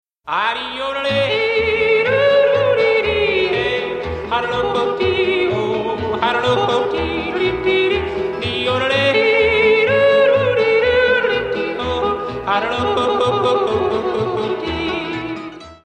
first species yodelling
opening yodel.